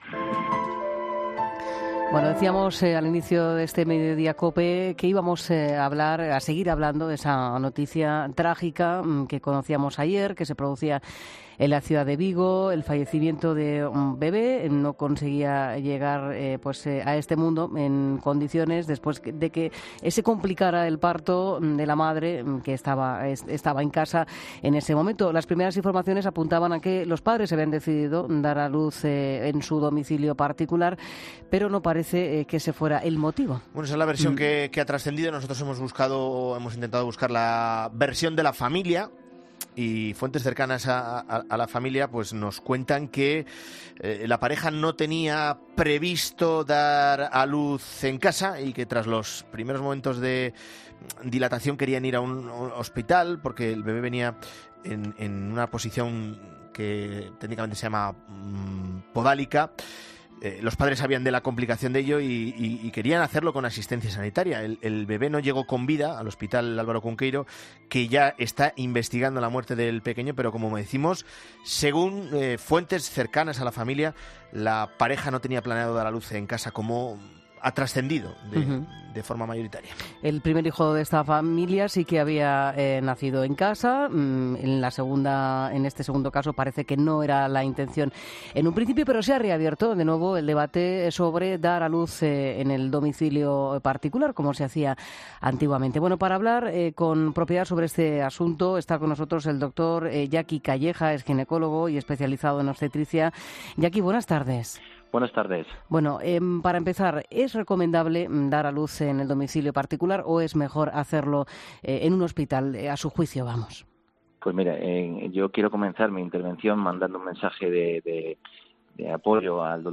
ginécologo especializado en obstetricia